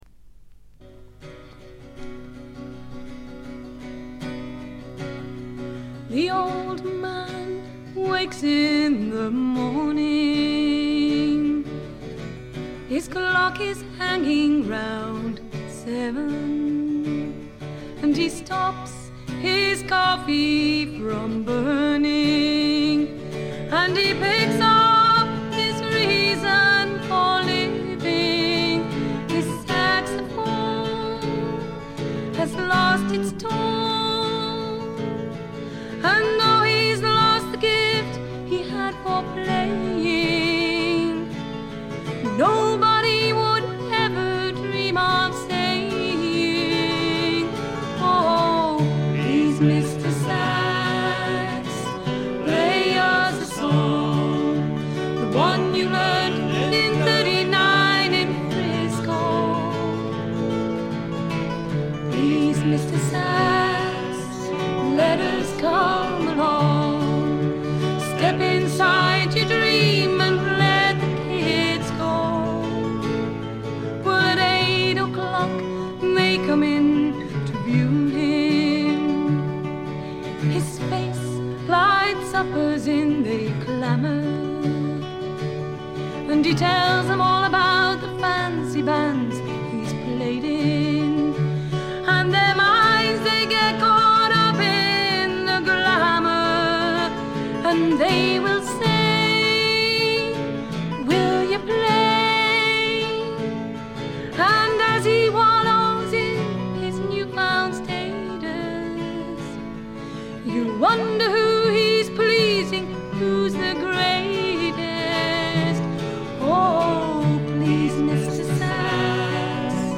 フィメールを含む4人組。
試聴曲は現品からの取り込み音源です。
banjo, whistle, vocals
Recorded at Mid Wales Sound Studio June 1976